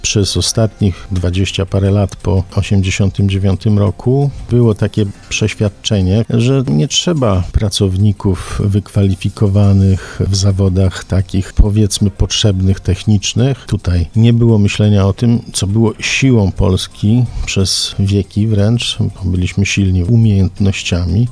Marek Suski, poseł Prawa i Sprawiedliwości podkreśla, że powstanie takich ośrodków z pewnością wpłynie na rozwój szkolenia zawodowego: